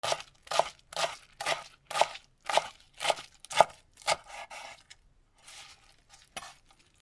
はくさいを切る